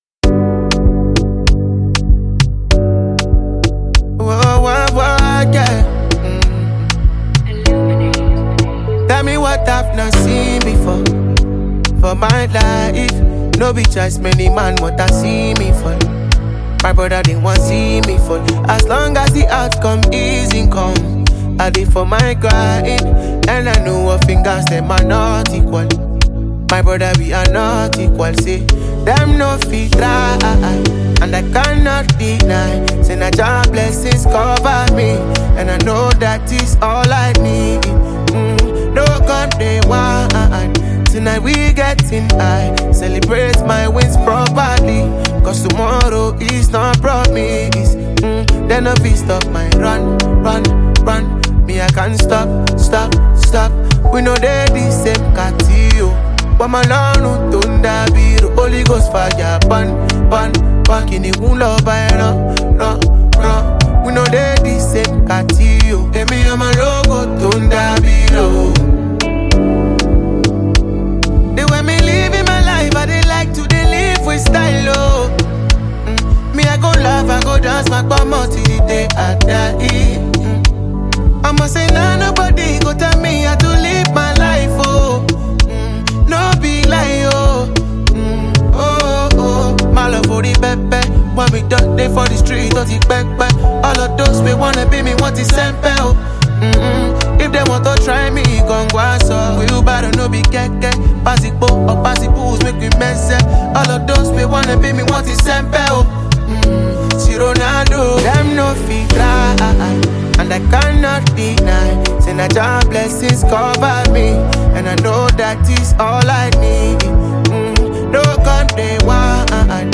soulful vibes
Afrobeats
authentic Afrobeats with depth and character